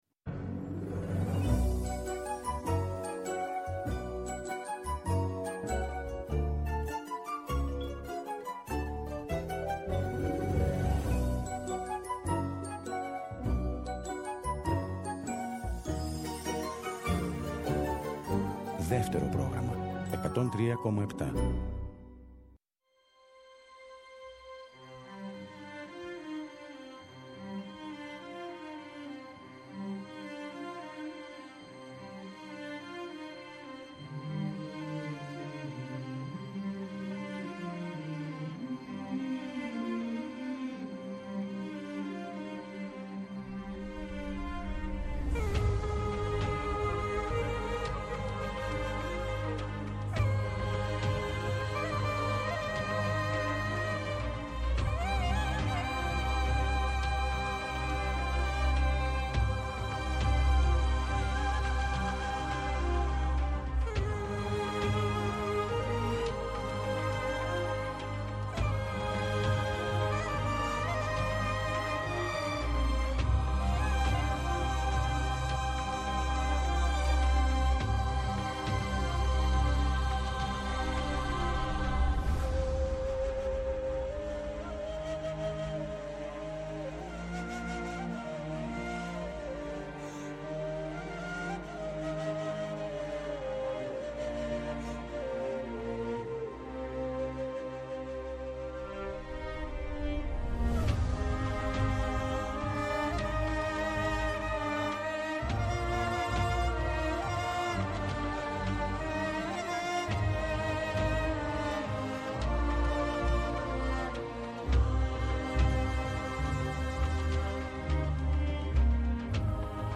Βόλτες στις μελωδίες, τους ήχους και τους στίχους από όλες τις εποχές του ελληνικού τραγουδιού, διανθισμένες με παρουσιάσεις νέων δίσκων, κινηματογραφικών εντυπώσεων, αλλά και ζεστές κουβέντες με καλλιτέχνες από τη θεατρική επικαιρότητα.